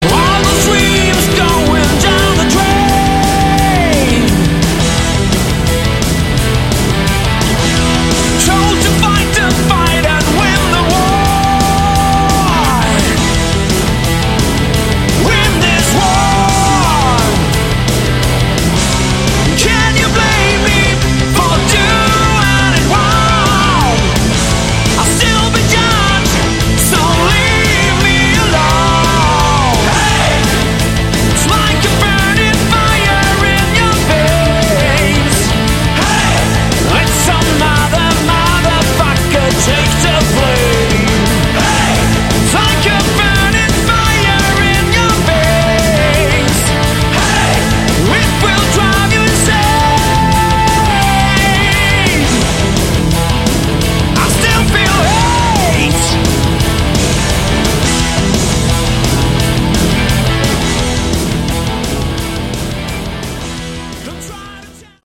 Category: Hard Rock
Guitars
Keyboards
Bass
Drums
Vocals